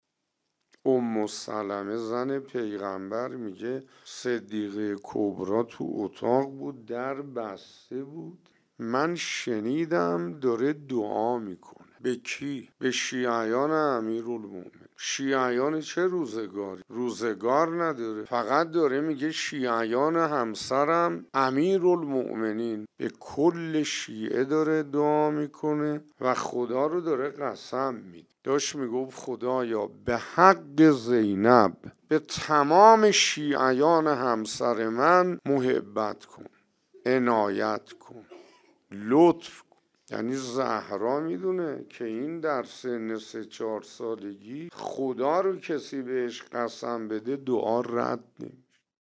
ایکنا به مناسبت ایام سوگواری شهادت دخت گرامی آخرین پیام‌آور نور و رحمت، مجموعه‌ای از سخنرانی اساتید اخلاق کشور درباره شهادت ام ابی‌ها (س) با عنوان «ذکر خیر ماه» منتشر می‌کند. قسمت نوزدهم «دعای حضرت زهرا(س) در حق شیعیان» را در کلام حجت‌الاسلام شیخ حسین انصاریان می‌شنوید.